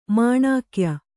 ♪ māṇākya